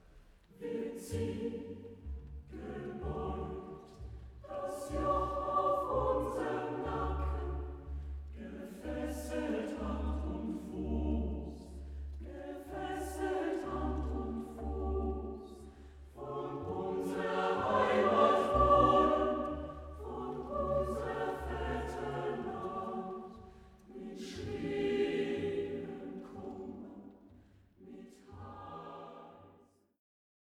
Rezitativ (Hanna)